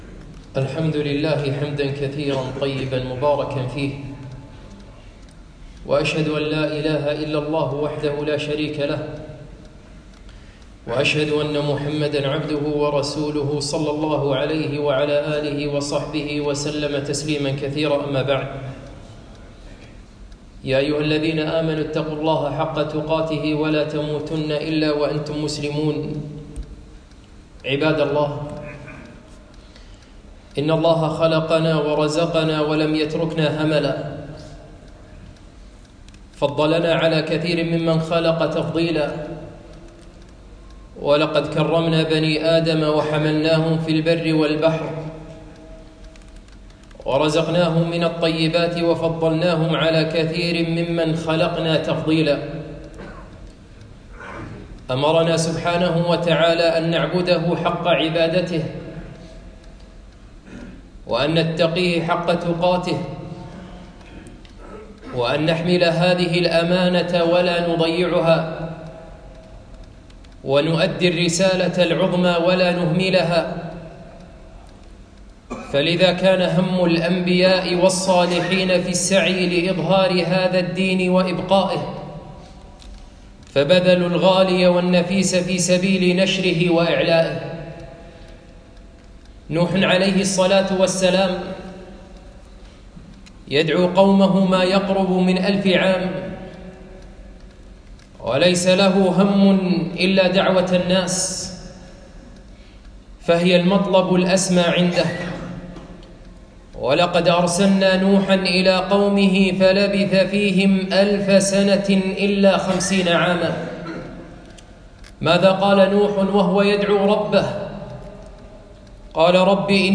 خطبة - ماهي اهتماماتك ؟